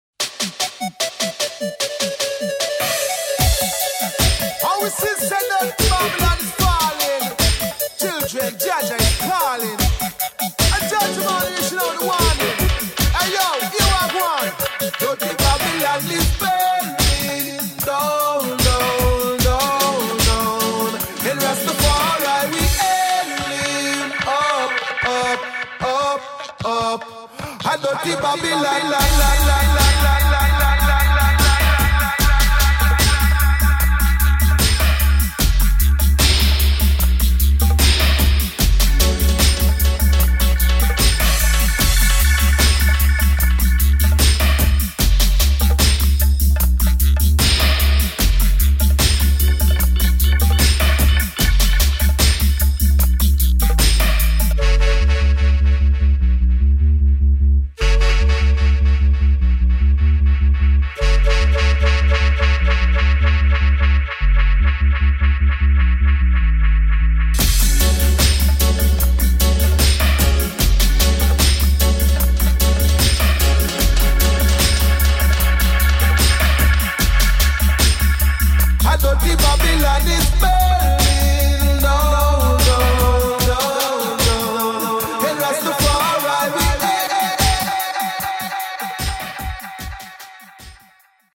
[ REGGAE | DUB ]
Dub Version